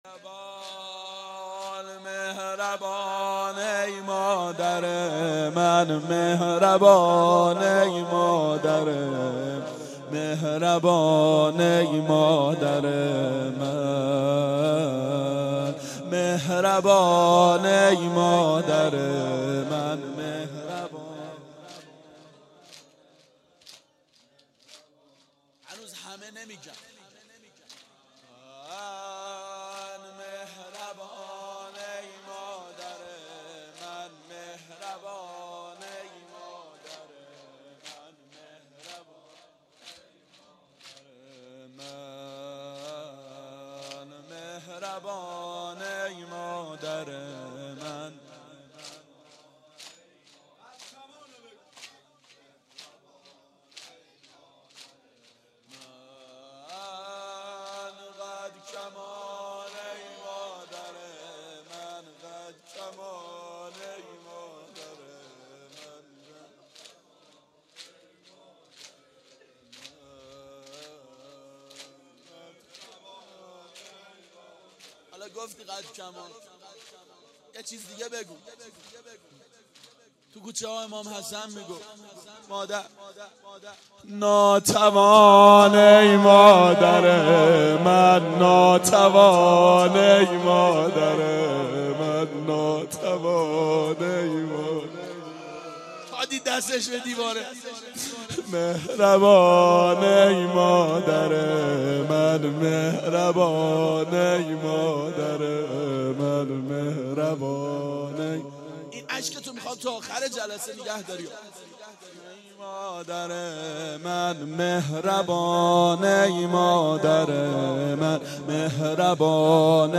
مهربان ای مادر من - زمینه
فاطمیه اول 92 عاشقان اباالفضل علیه السلام منارجنبان